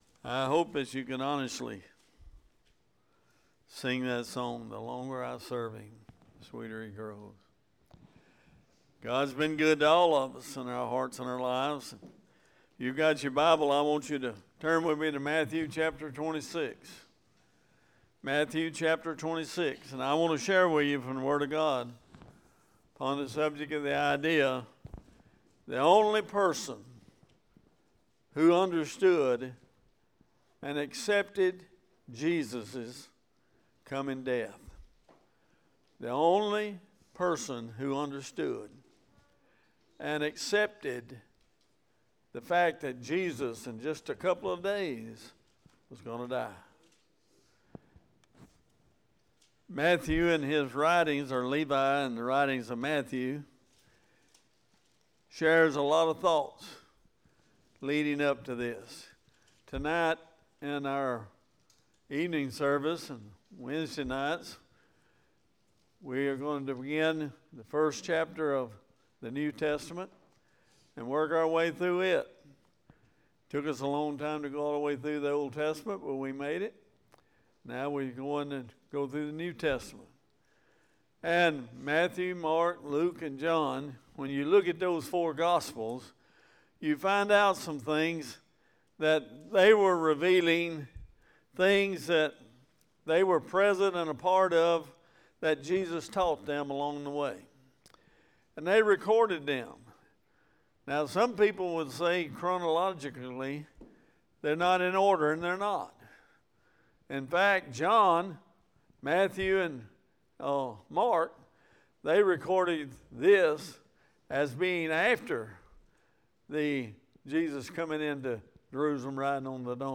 Sermons | Bexley Baptist Church